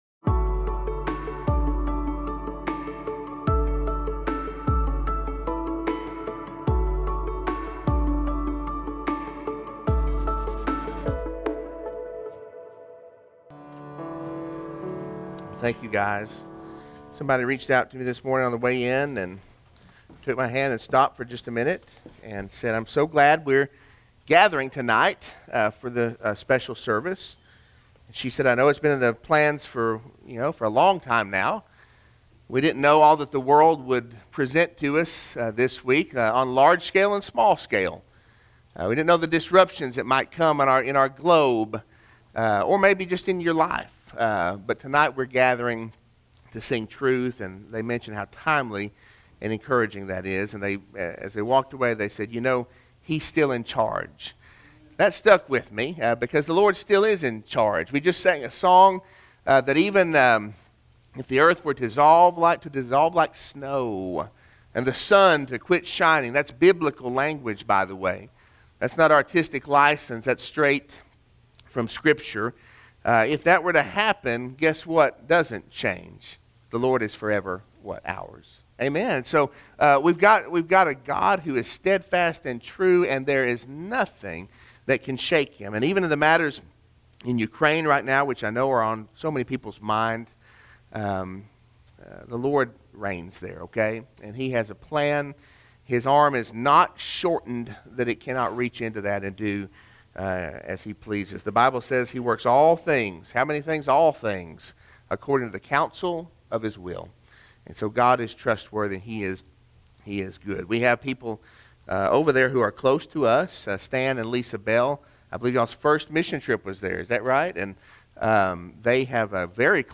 Sermon-2-27-22-audio-from-video.mp3